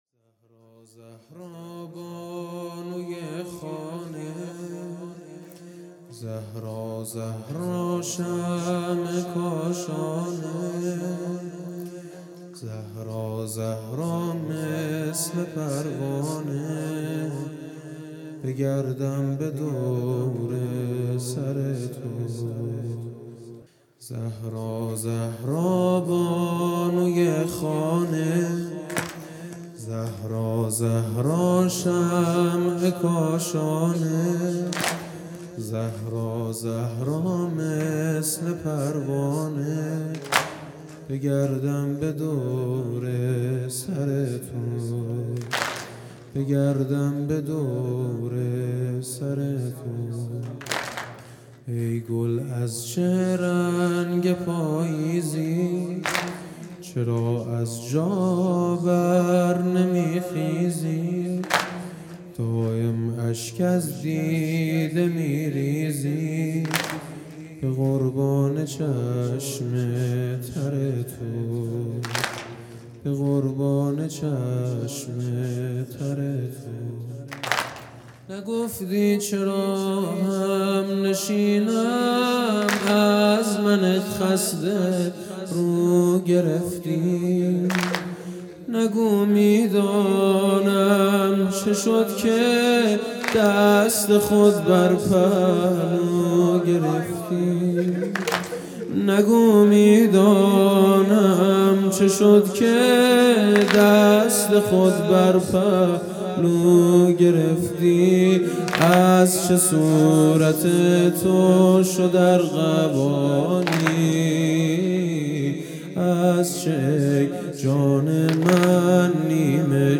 زهرا زهرا بانوی خانه|جلسه هفتگی